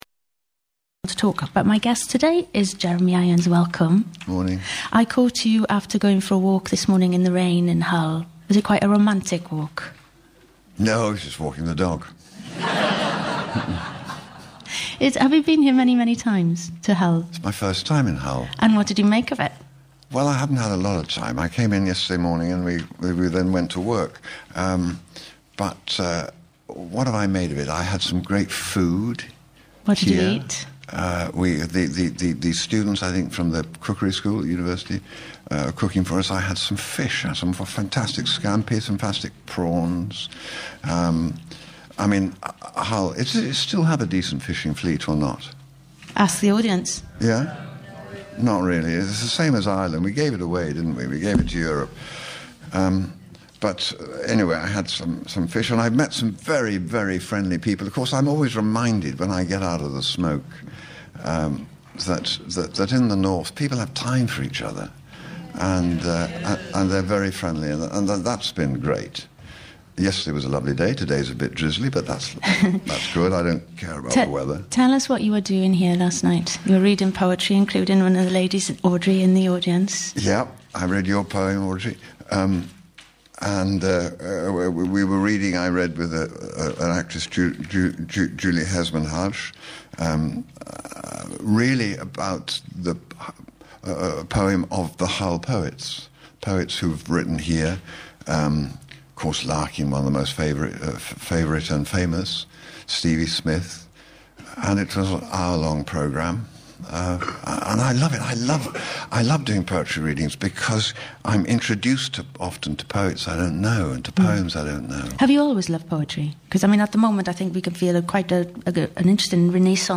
Jeremy Irons was a guest of Cerys Matthews on BBC Radio 6, live from Hull, on Sunday 1 October 2017.
Click on the player below to listen to just Jeremy’s portion of the broadcast: